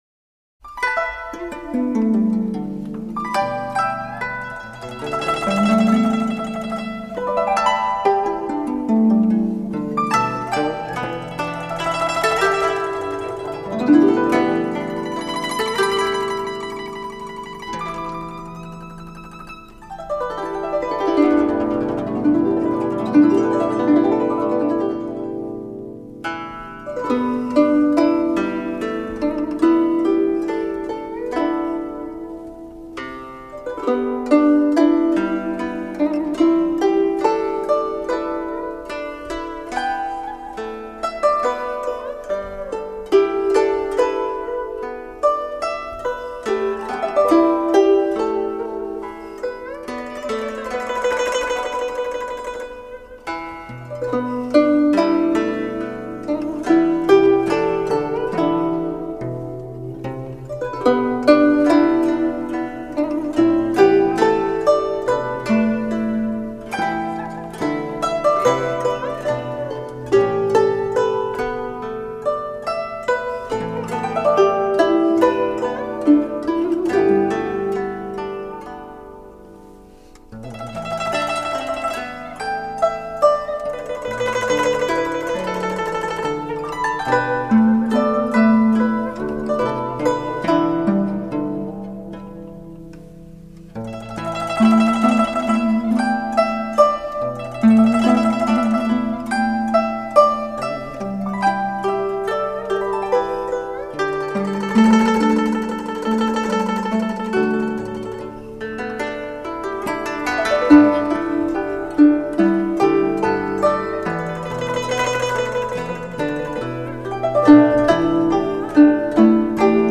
有好几首曲目都是将中国民乐和交响乐合奏的新尝试